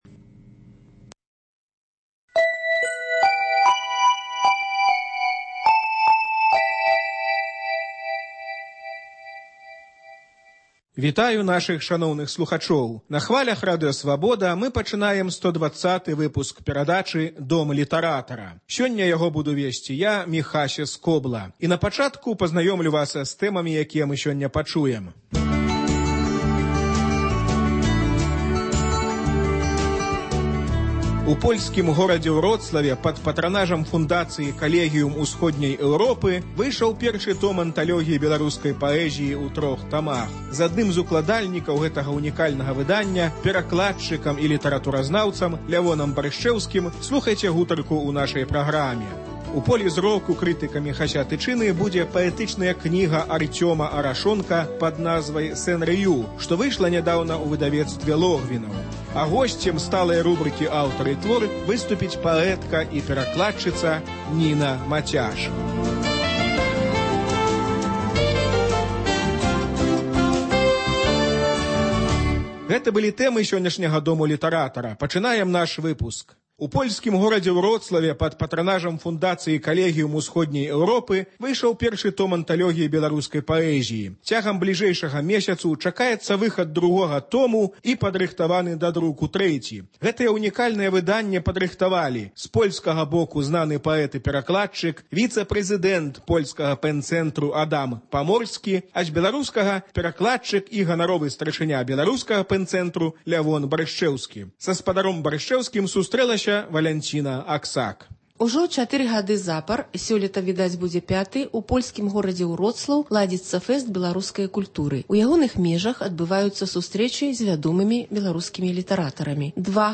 Літаратурны агляд з удзелам Лявона Баршчэўскага